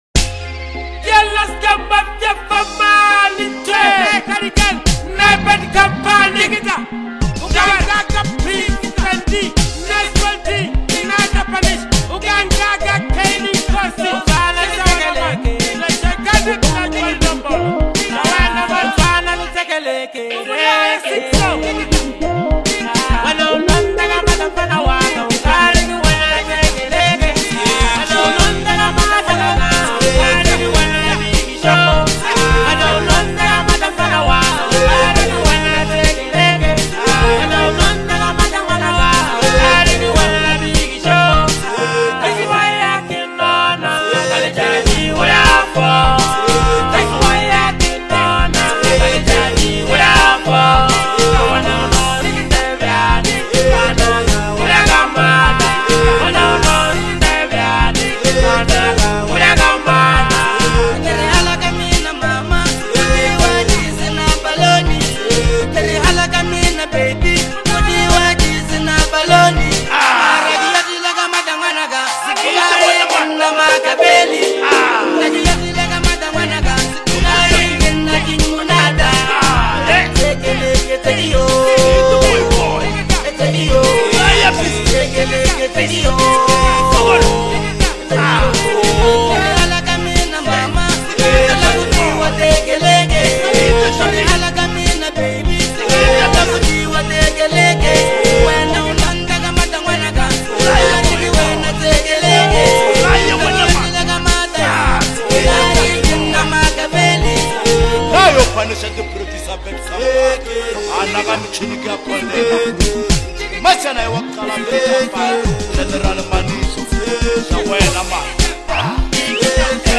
Dhamara